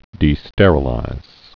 (dē-stĕrə-līz)